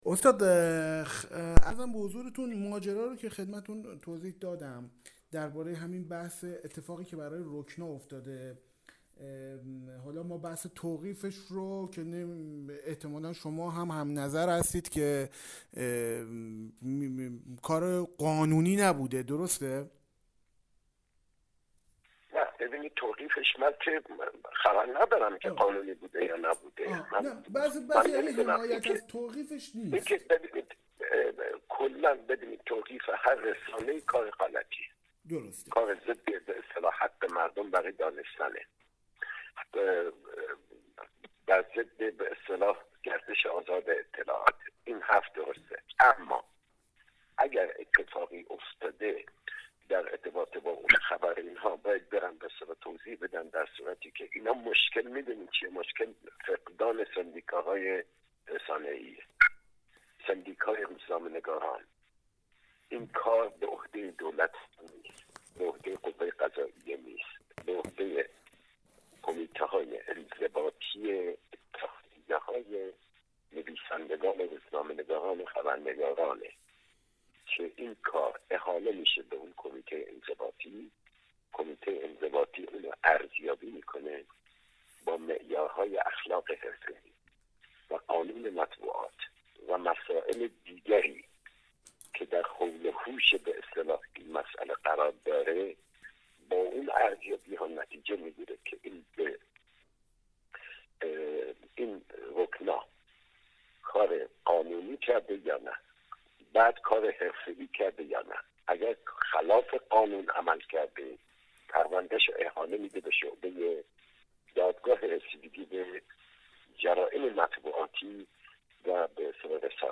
دلیلی که برای توقیف رکنا اعلام شد، به نوبه خود بار دیگر بحث‌های حرفه‌ای در میان اهالی رسانه در مورد الزامات حرفه‌ای «روزنامه‌نگاری بحران» را پیش کشید. آنچه می‌شنوید حاصل گفتگوی خبرنگار «فراز» با ماشالله شمس‌الواعظین، استاد روزنامه‌نگاری و روزنامه‌نگار پیشکسوت در مورد بایدها و نبایدها و خط قرمزهای حرفه‌ای «روزنامه‌نگای بحران» است.